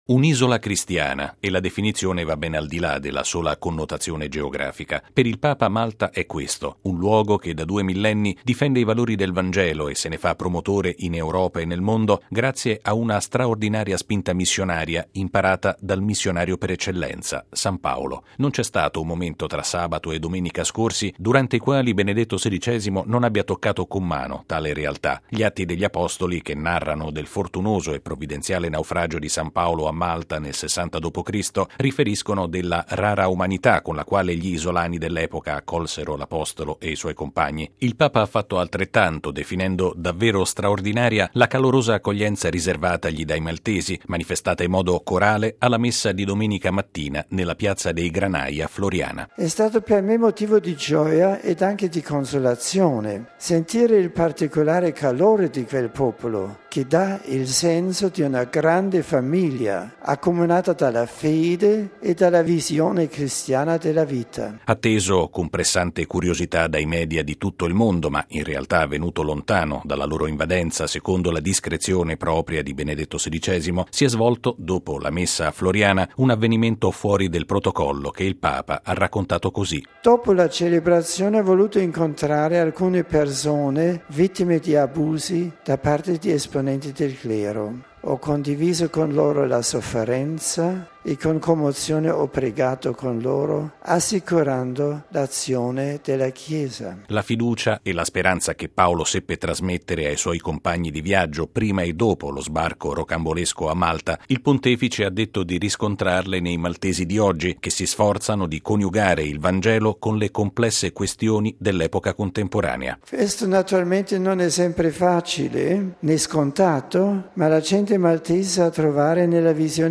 La cronaca